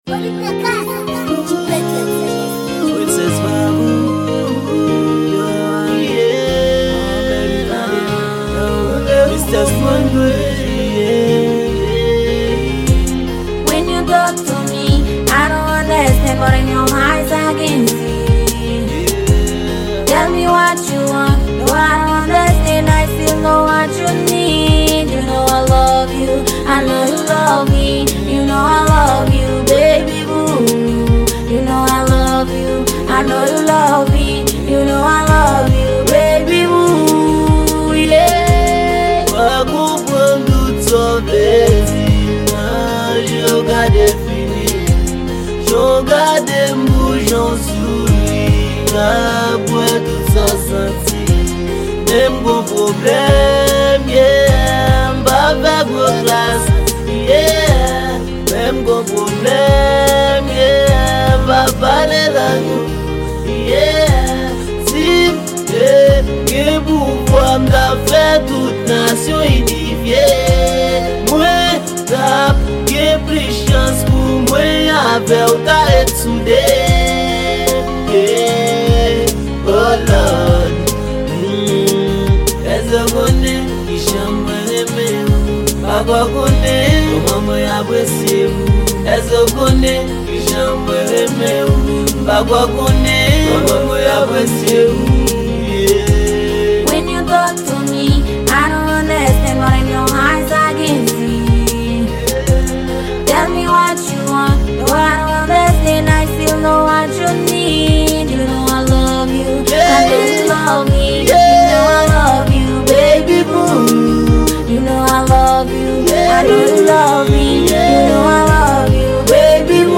Genre: Rap Kreyòl.